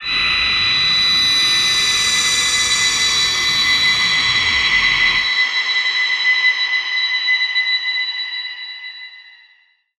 G_Crystal-B7-f.wav